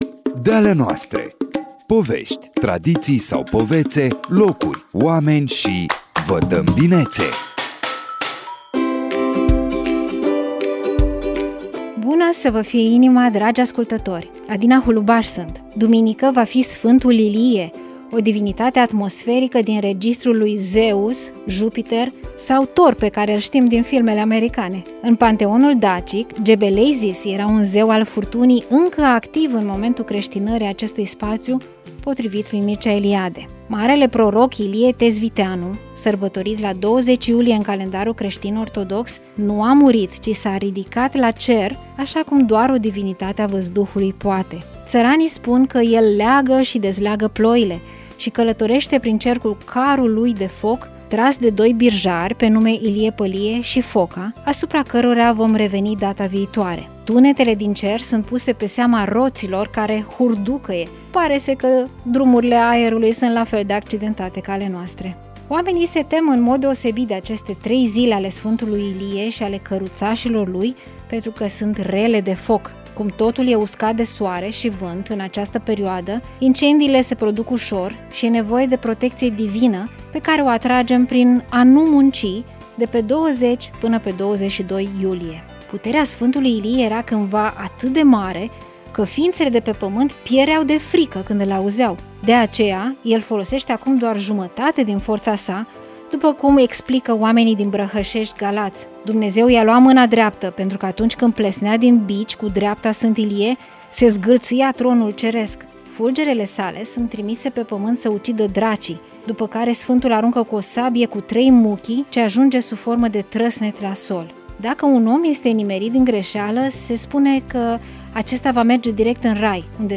pe frecvențele Radio România Iași: